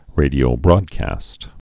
(rādē-ō-brôdkăst)